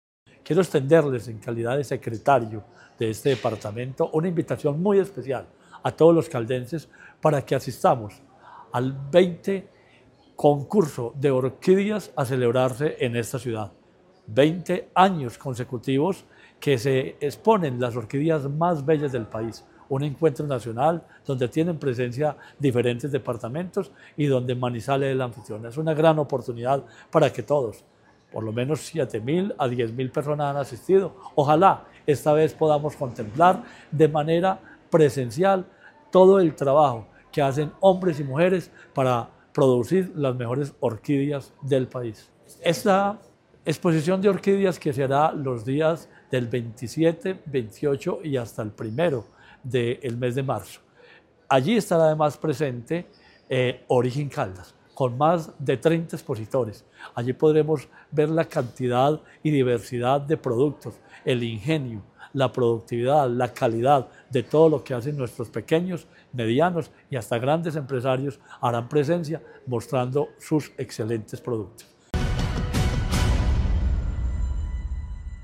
Marino Murillo Franco, secretario de Agricultura y Desarrollo Rural de Caldas.
Marino-Murillo-Franco-PARTICIPACION-ORIGEN-CALDAS-XX-FERIA-NACIONAL-DE-ORQUIDEAS-1-online-audio-converter.com_.mp3